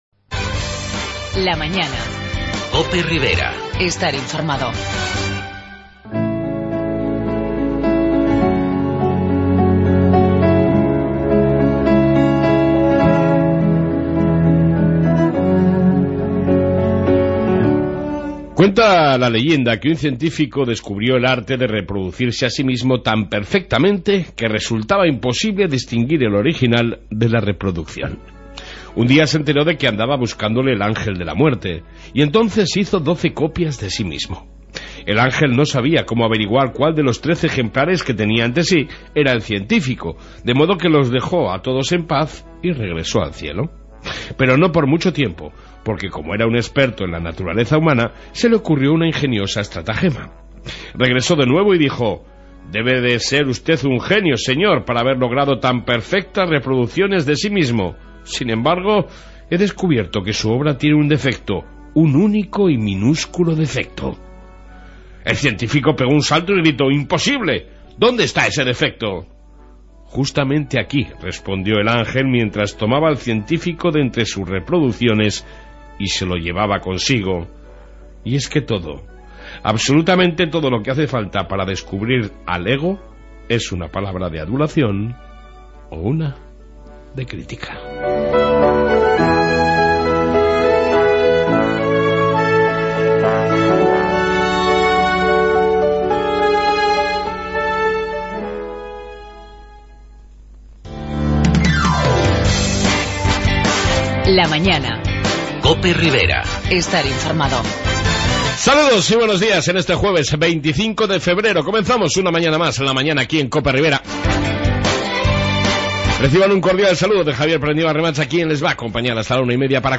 Reflexión diaria y amplia entrevista sobre la Vivienda en la Ribera